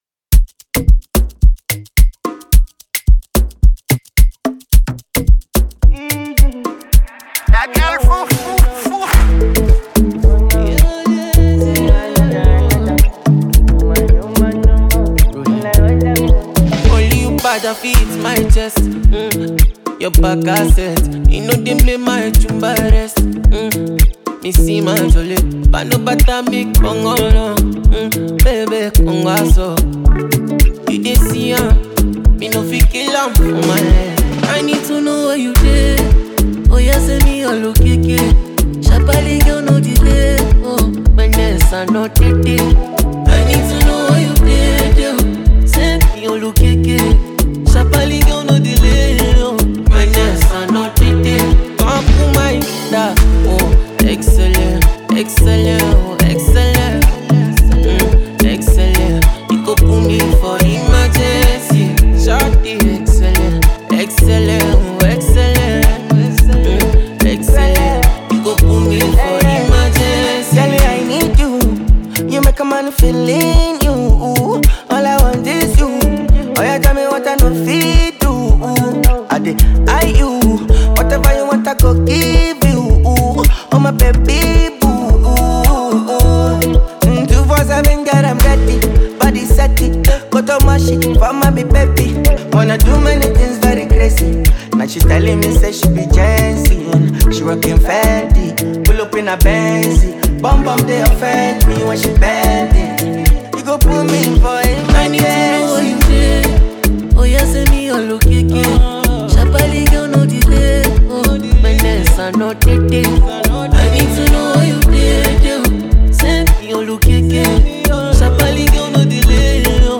The song features afrobeat star